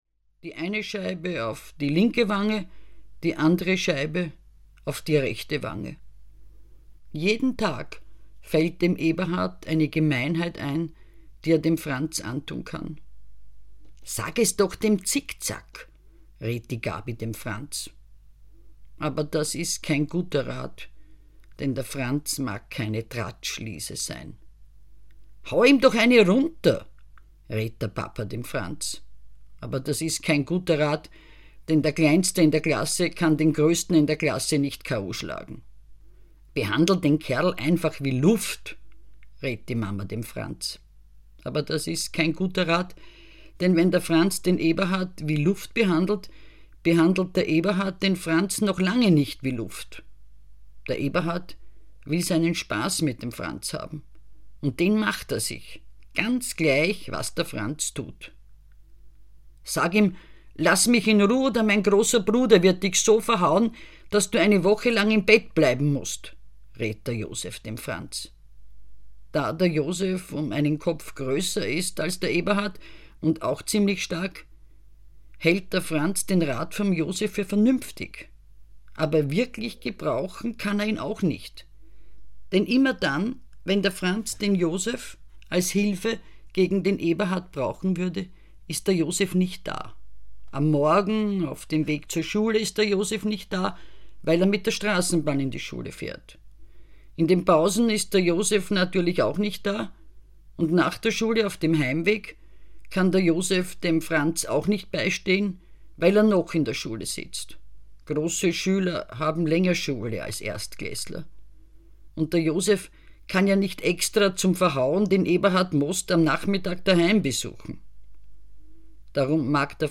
Schulgeschichten vom Franz - Christine Nöstlinger - Hörbuch